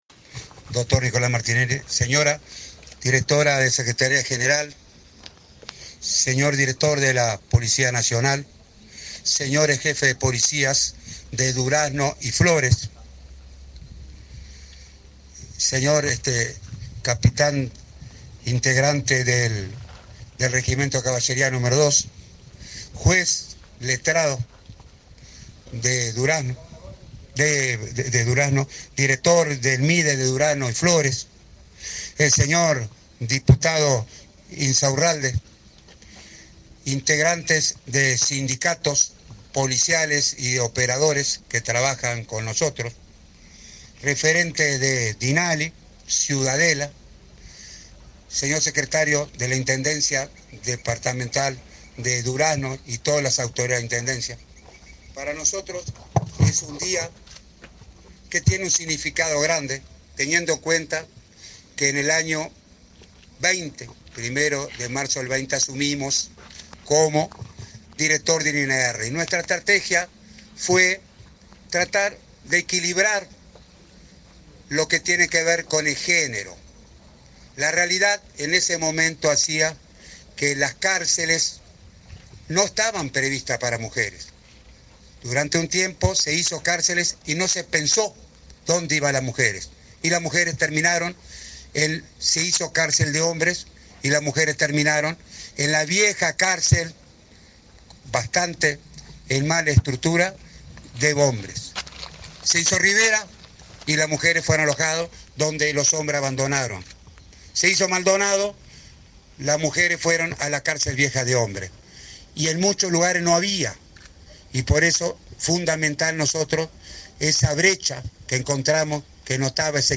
Palabras del director del INR, Luis Mendoza 12/06/2024 Compartir Facebook X Copiar enlace WhatsApp LinkedIn El Ministerio del Interior inauguró, este 12 de junio, la cárcel de mujeres en Durazno. Disertó en el evento el director del Instituto Nacional de Rehabilitación (INR), Luis Mendoza.